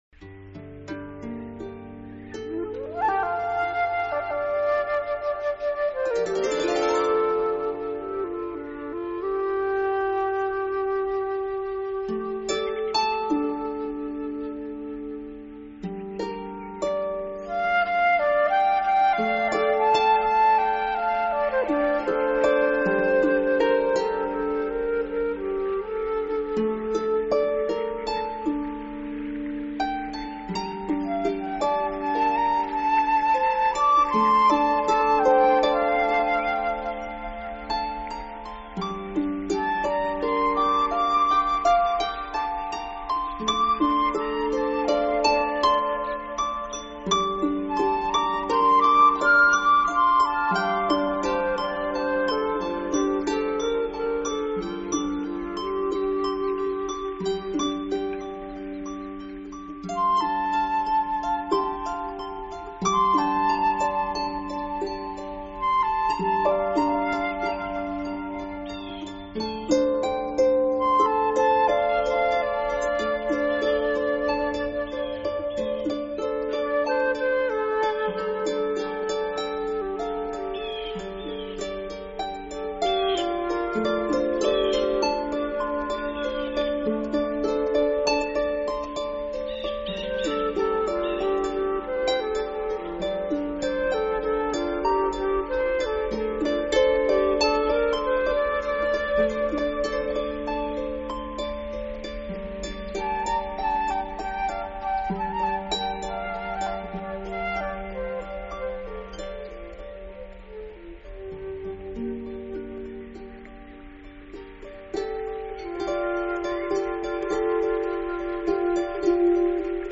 专辑语言：纯音乐
音乐整体显得比较平静，也许只有亲临荷花池，才能体会到音乐的意境。